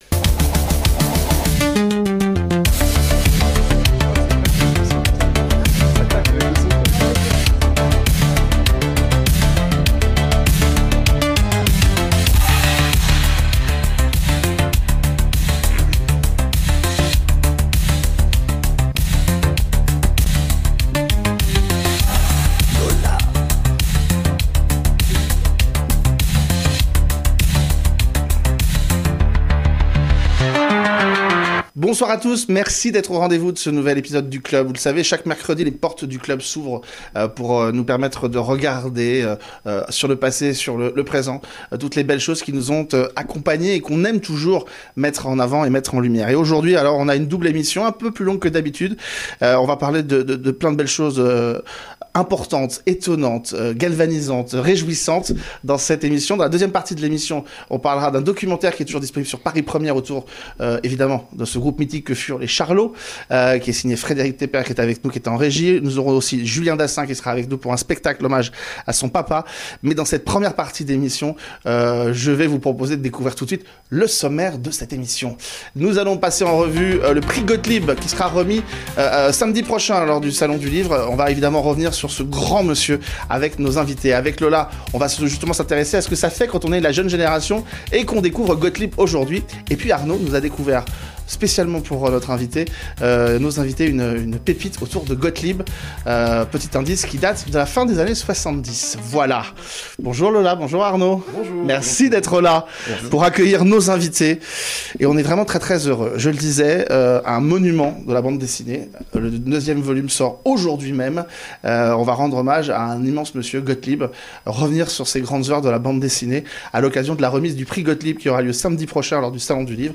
A l’occasion de la remise du Prix Gotlib lors du Festival du Livre de Paris, nous sommes très heureux de recevoir cette semaine en studio